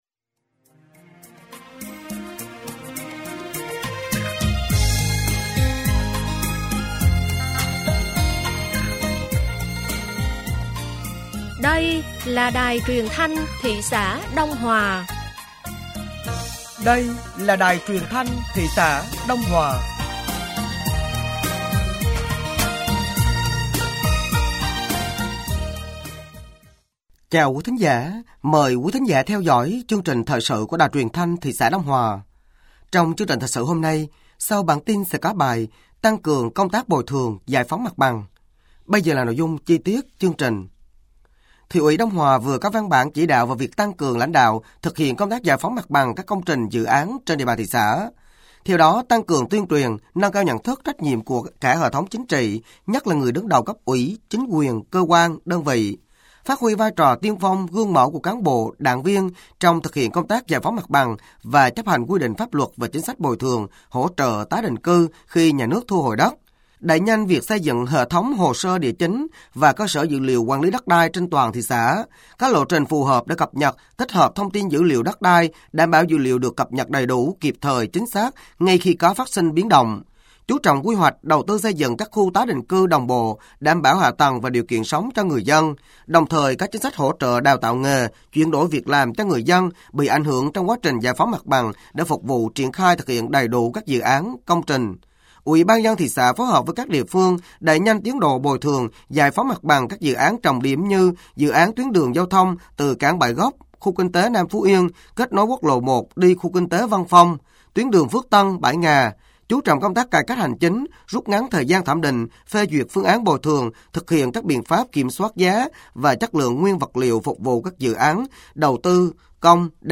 Thời sự tối ngày 10 và sáng ngày 11 tháng 3 năm 2025